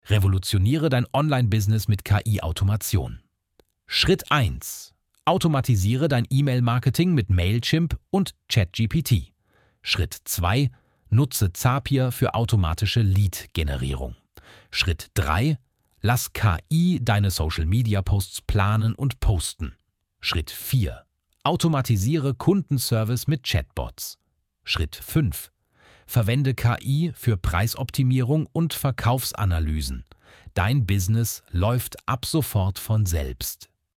Automatisch generierte Videos mit deutscher KI-Stimme
• Stimme: Stefan (ElevenLabs German)
🎤ElevenLabs TTS: Stefan Voice (Deutsche Native Speaker)